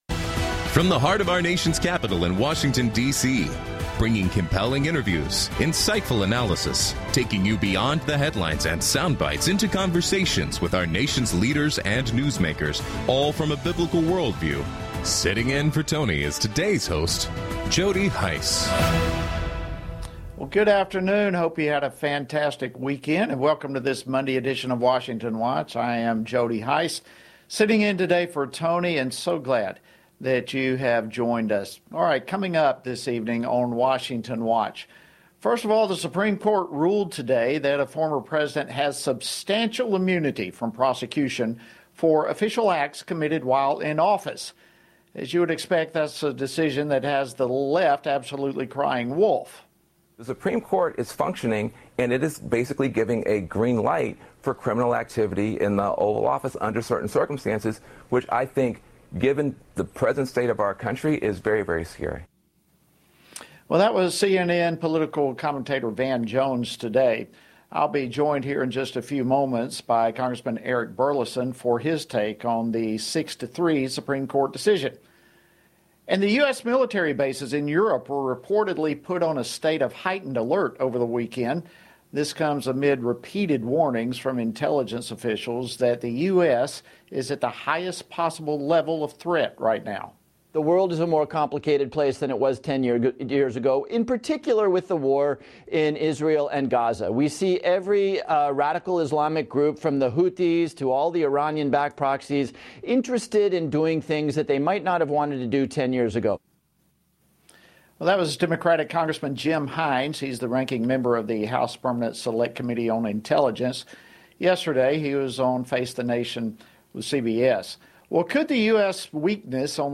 Hard hitting talk radio never has been and never will be supported by the main stream in America!